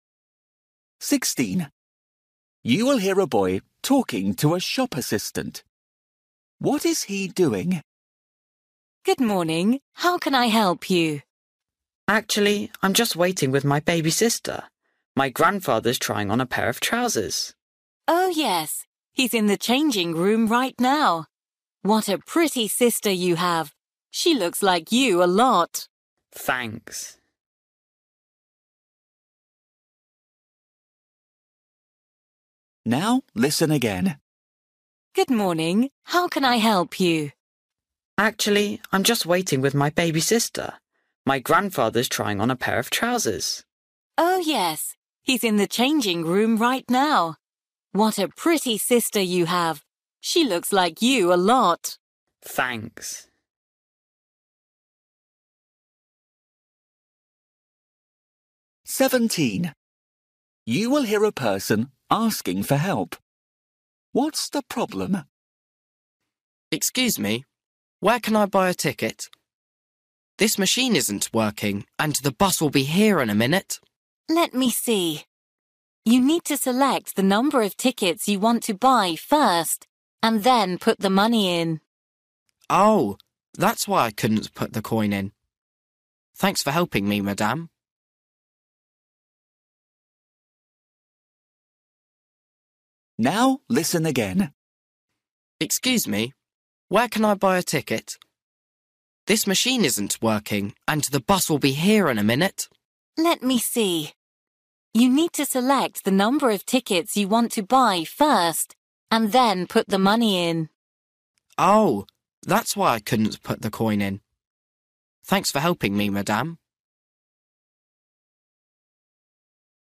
Listening: everyday short conversations
16   You will hear a boy talking to a shop assistant. What is he doing?
19   You will hear two friends talking about a trip. Where are they meeting up?